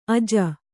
♪ aja